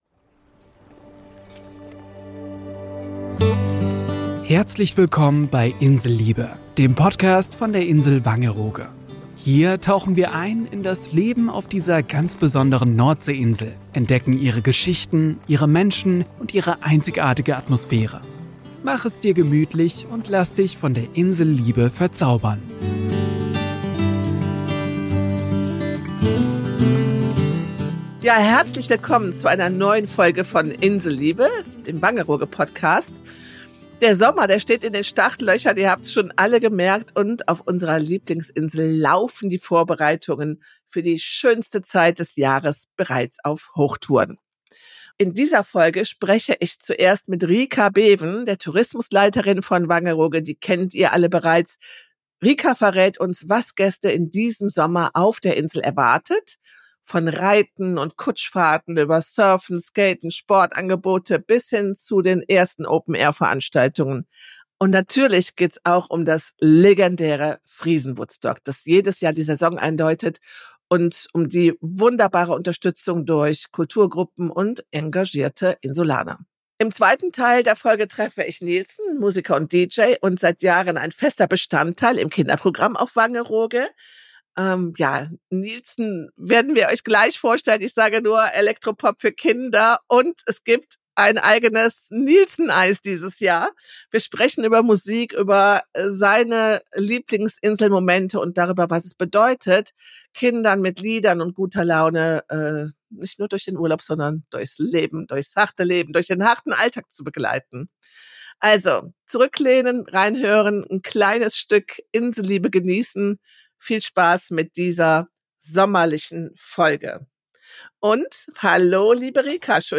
Viel Freude und Sonnenschein bei dem Talk mit Meeresrauschen!